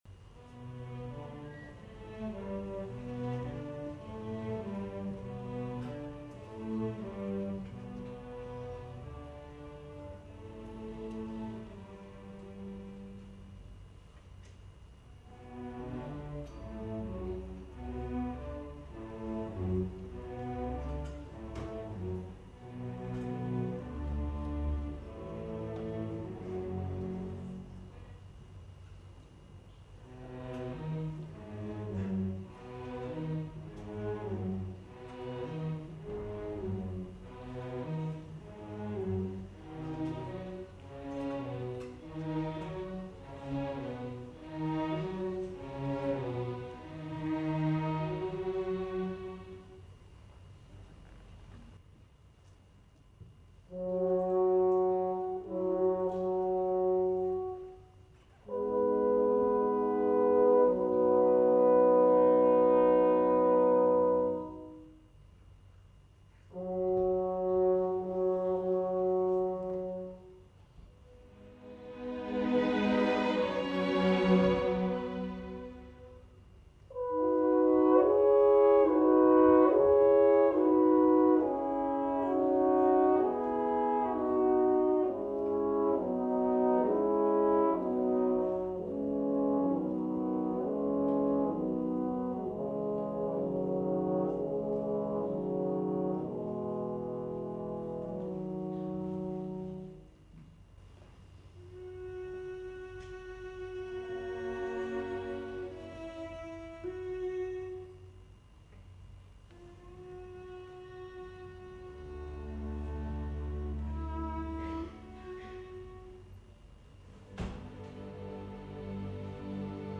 Ave Maria για 4 κόρνα και Ορχήστρα Εγχόρδων
Συμφωνική Ορχήστρα Φιλαρμονικής Εταιρείας Κέρκυρας Σολίστ